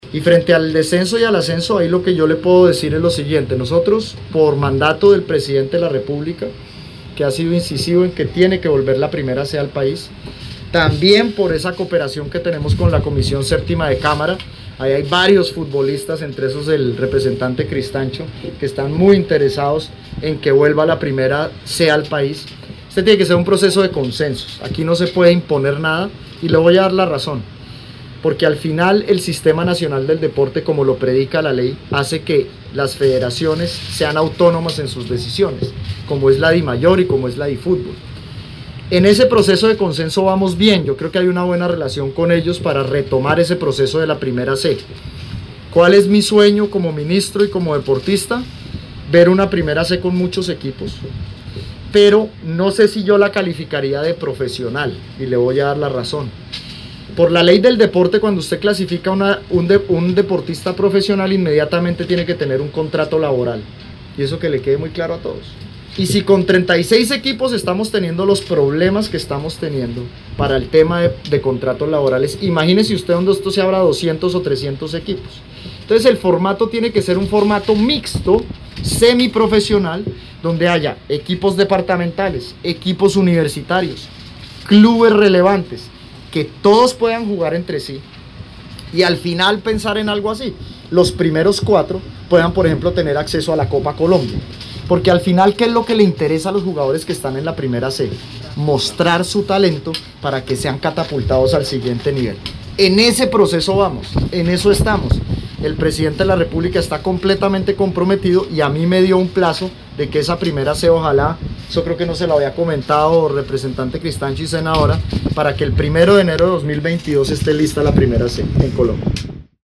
El Ministro del Deporte Ernesto Lucena anunció en entrevista a medios de Yopal – Casanare que la intención del Gobierno Nacional es darle apertura al torneo de primera “C” en enero del 2022. El formato podría ser con ascenso y descenso entre la “B” y la “C”.